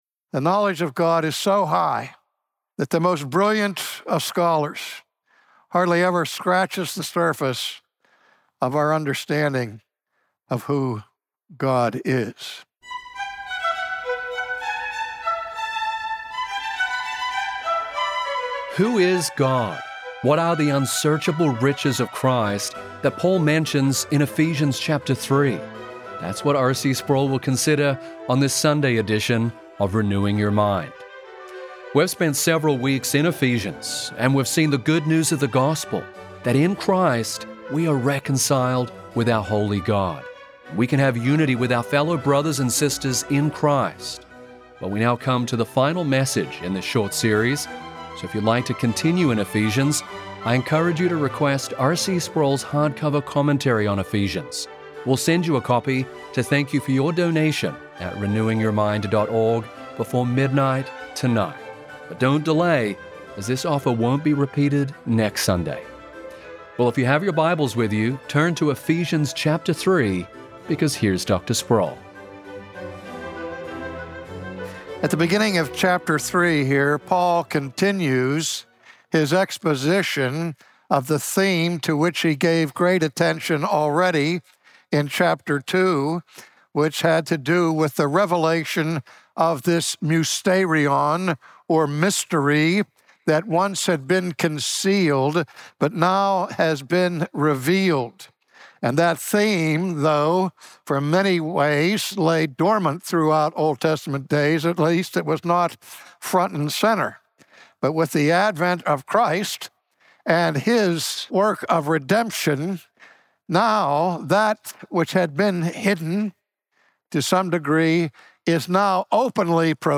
From his Ephesians sermon series, today R.C. Sproul considers the role of the church in spreading the knowledge of God.